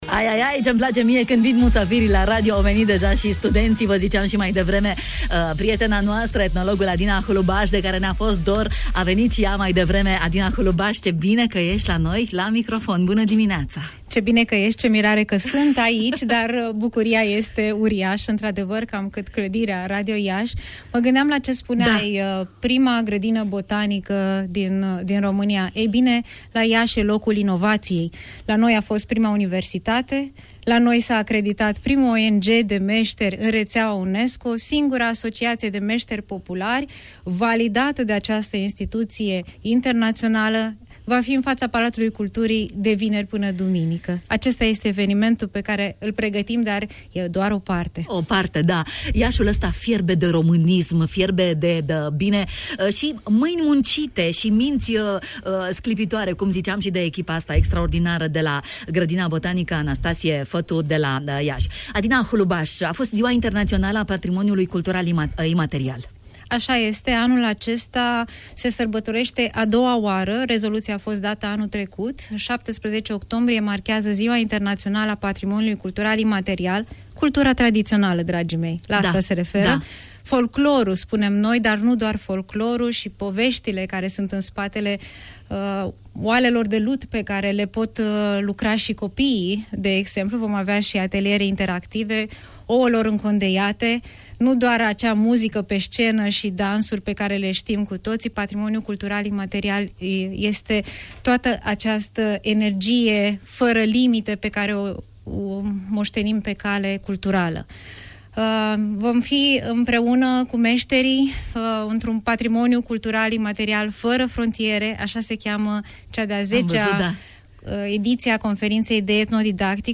în matinalul de la Radio România Iași: